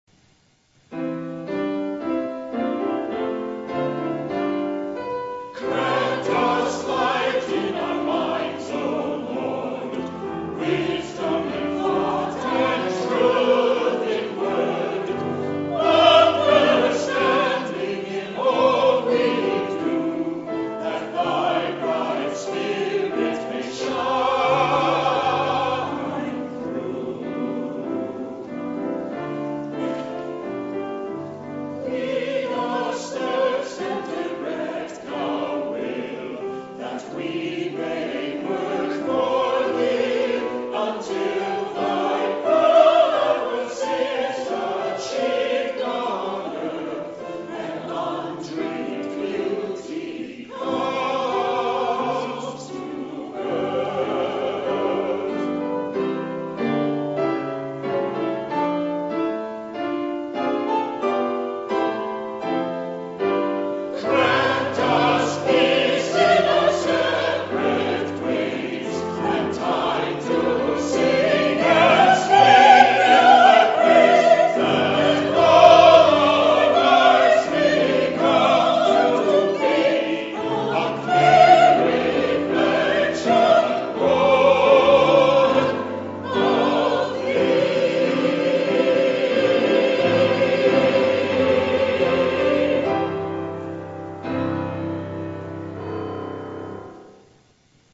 The Second Reformed Chancel Choir sings "Grant Us Light" by Eric Thiman